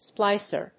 アクセント・音節splíc・er
音節splic･er発音記号・読み方spláɪsər